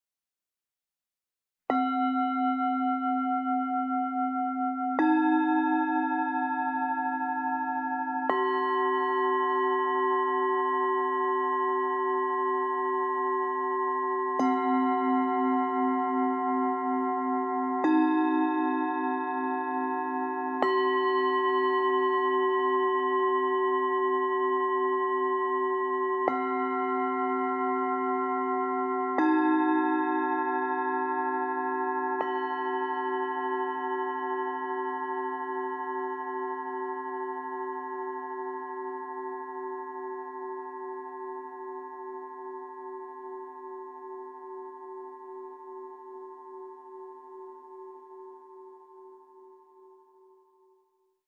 The Meinl Sonic Energy Universal Series Singing Bowls are quality-sounding instruments at great value.